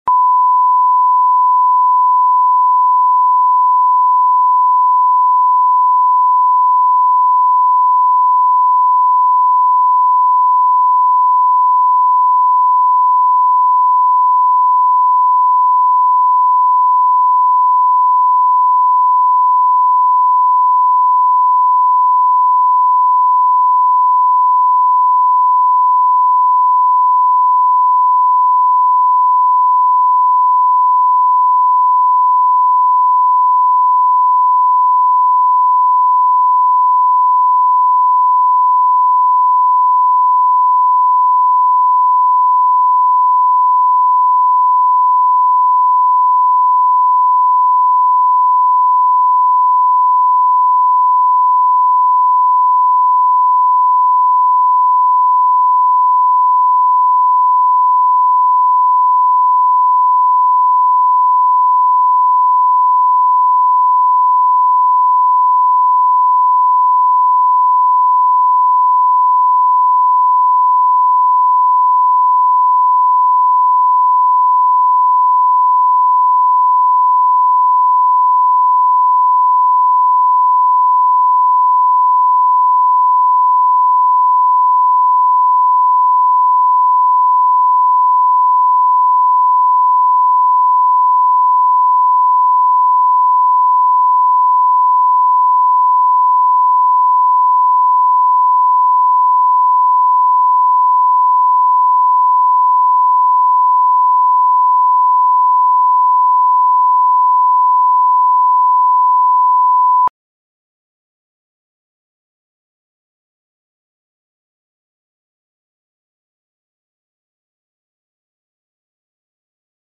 Аудиокнига Дневники отличника | Библиотека аудиокниг